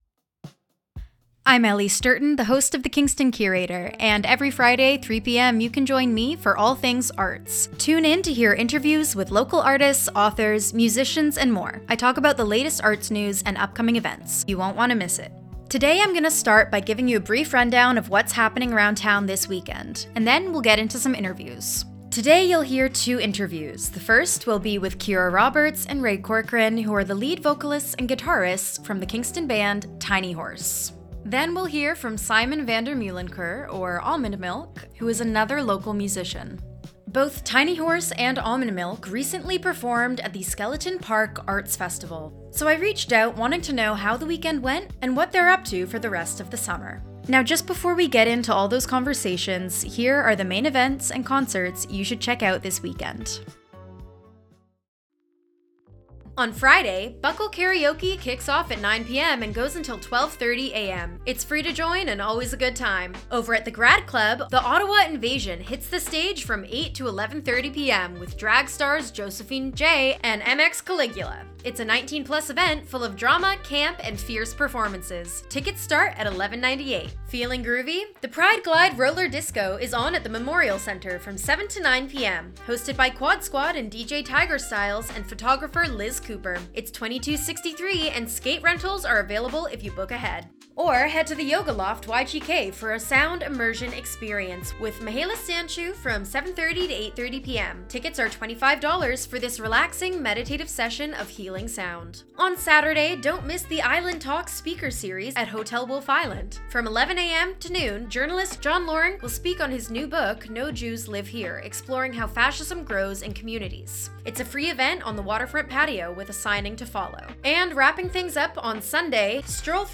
This episode features in-depth interviews with local Kingston artists Tiny Horse, and Almond Milk!